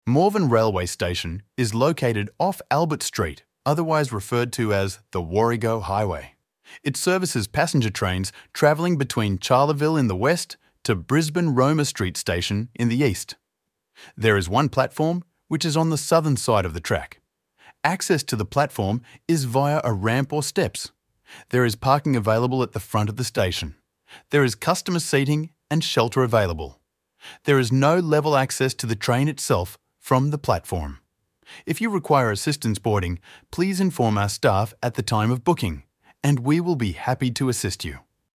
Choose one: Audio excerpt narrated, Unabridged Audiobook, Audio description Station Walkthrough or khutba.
Audio description Station Walkthrough